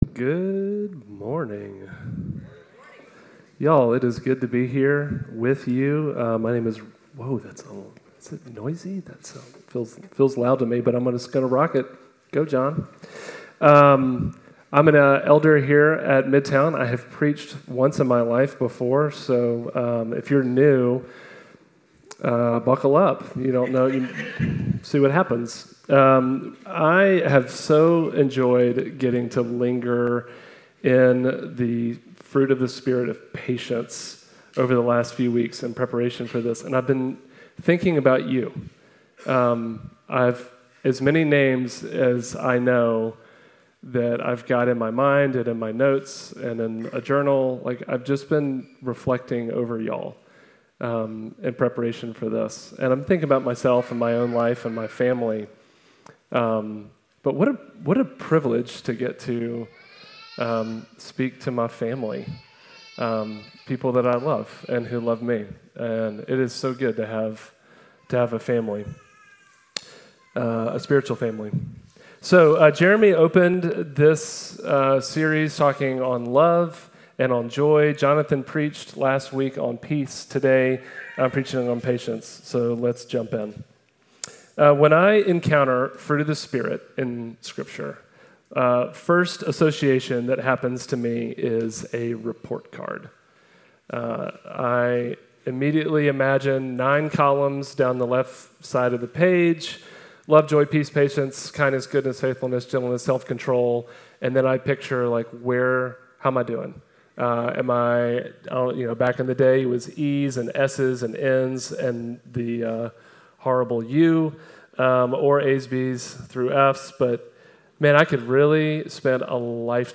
Midtown Fellowship Crieve Hall Sermons Patience Jun 22 2025 | 00:35:18 1x 00:00 / 00:35:18 Subscribe Share Apple Podcasts Spotify Overcast RSS Feed Share Link Embed